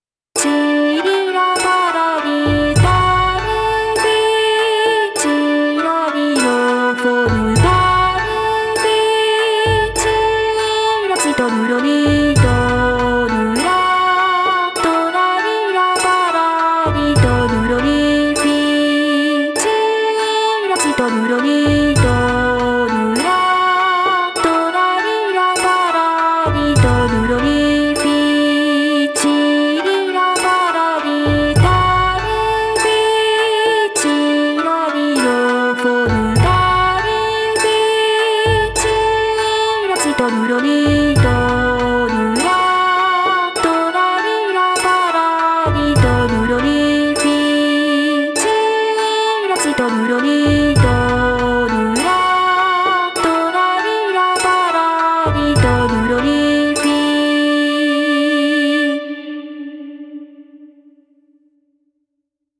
ついでに、篳篥の譜に唱歌（メロディーを覚えるための歌）が書いてあったので、ボーカロイド（メグッポイド）に歌ってもらいました。
聴いていただければ分かるとおり、とってもポップで明るい！！です！
楽器は、篳篥の音をオーボエに、笛をピッコロに、琵琶を三味線にしてます。